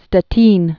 (stə-tēn, shtĕ-)